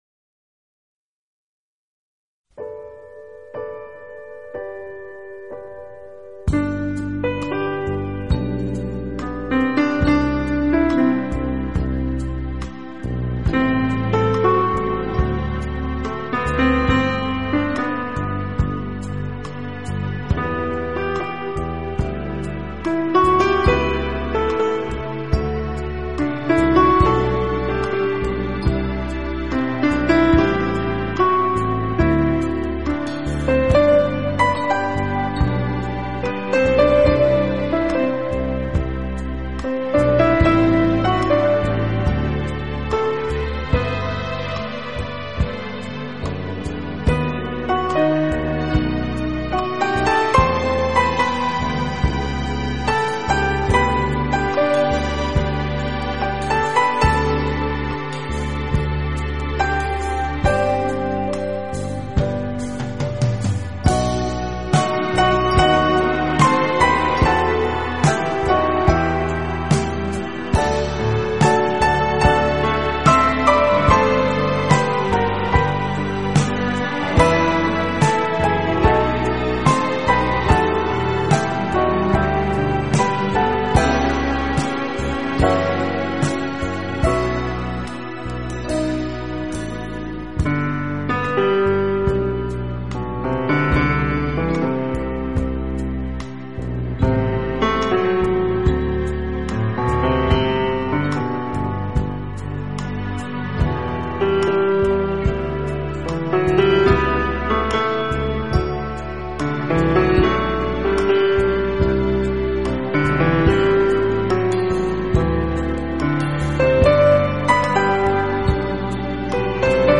*Genre ...........: Instrumental *